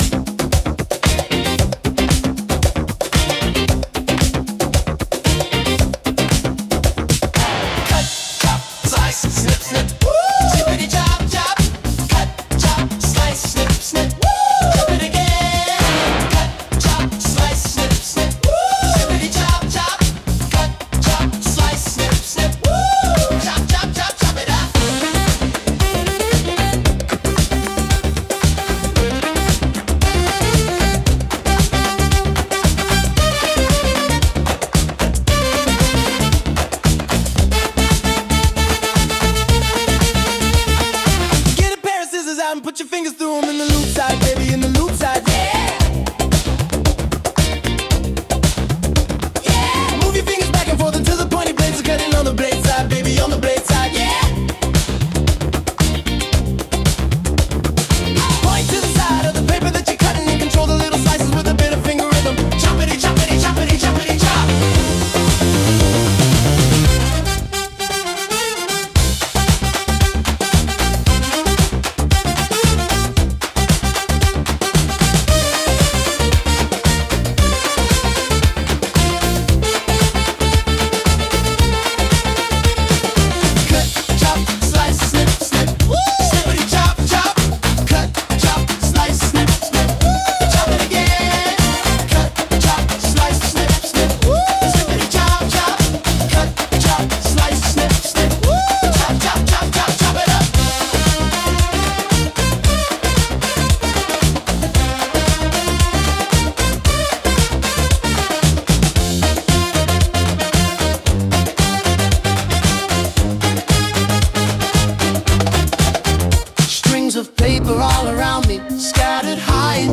Sung by Suno